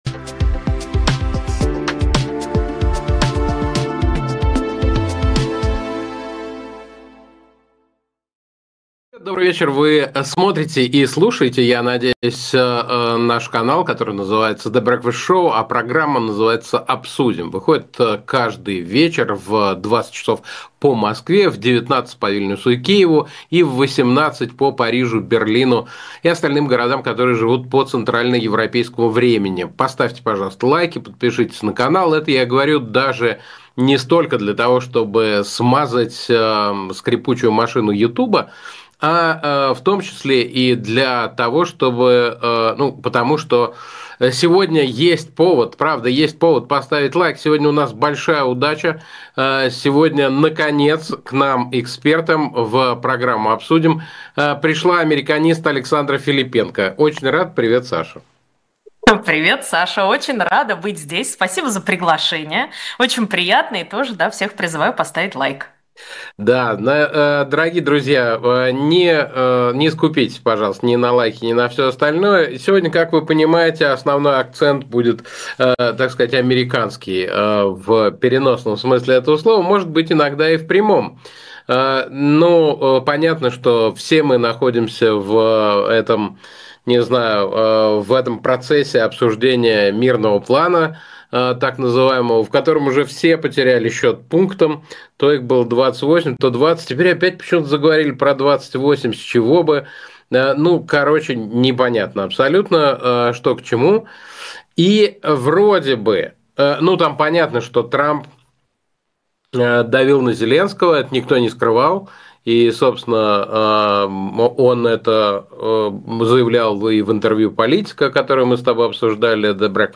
Эфир ведёт Александр Плющев